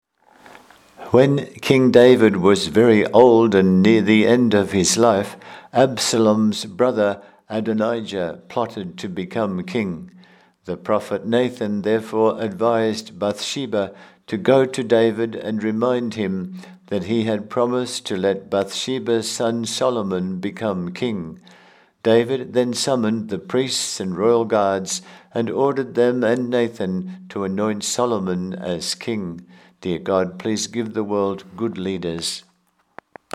My recording of this reading